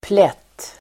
Uttal: [plet:]